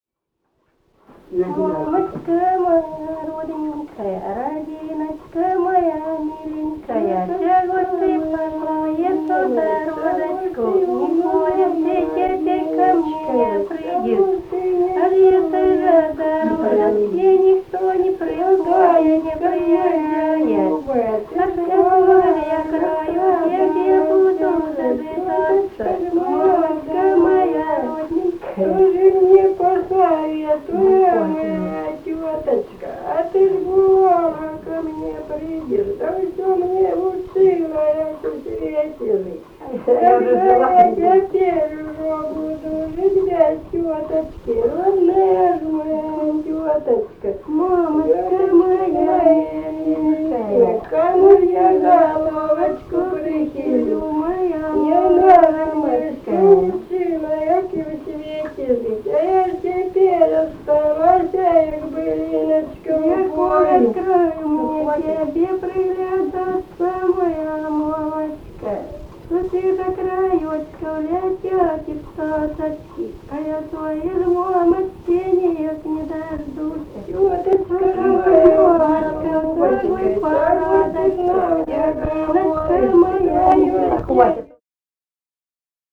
| diskname = Музыкальный фольклор Климовского района
«Мамочка моя родненькая» (причитание).